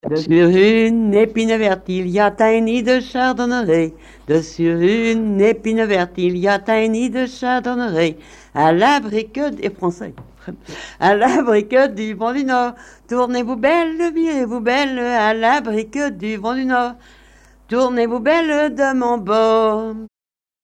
Patois local
Couplets à danser
danse : branle
chansons traditionnelles
Pièce musicale inédite